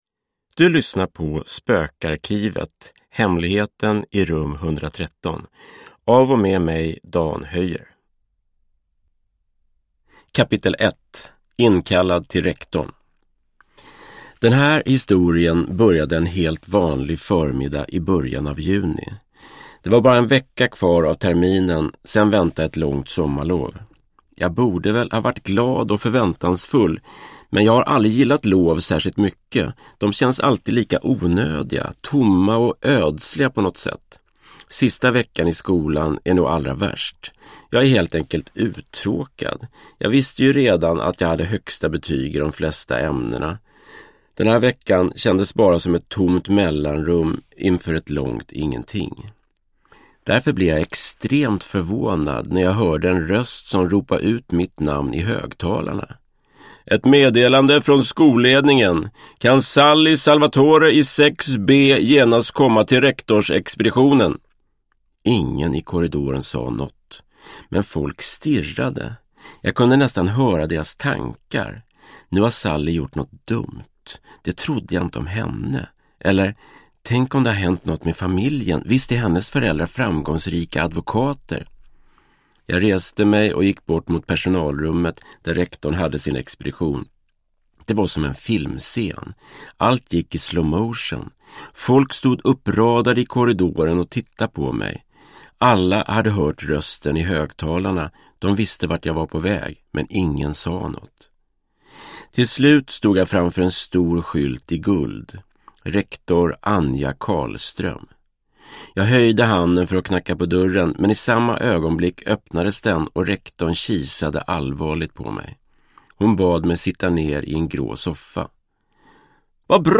Hemligheten i rum 113 – Ljudbok – Laddas ner